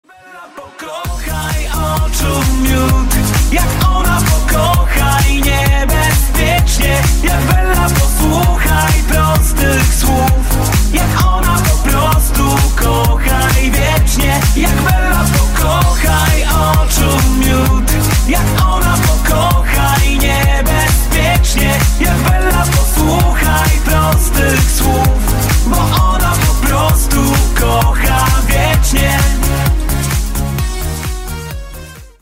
Kategorie Disco Polo